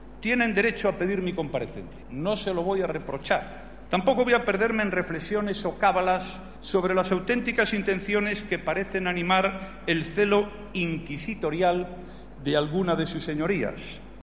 Comparece en el Congreso